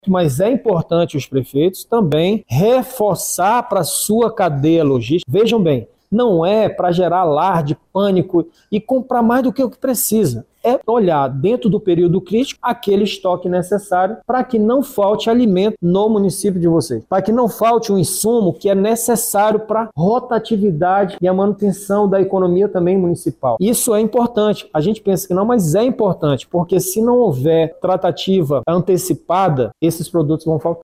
Segundo o secretário executivo da Defesa Civil do Amazonas, coronel Máximo, o comércio deve se programar para o momento de seca.